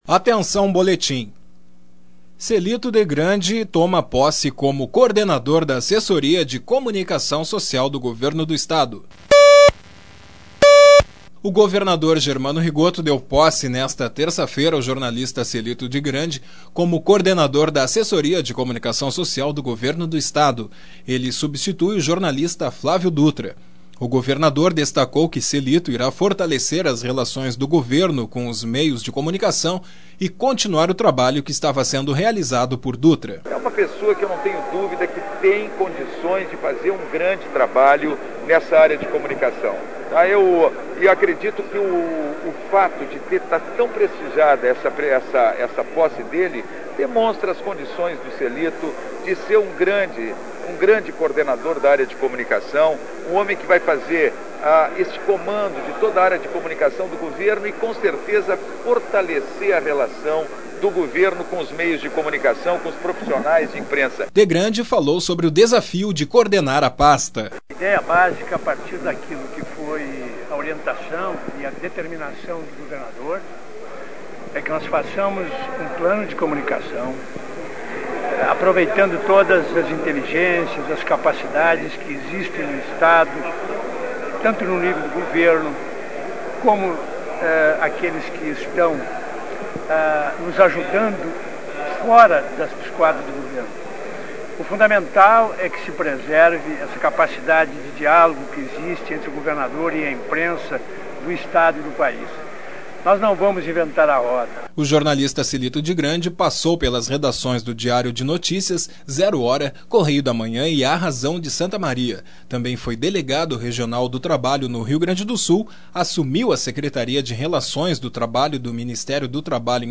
Sonoras: governador Germano Rigotto e coordenador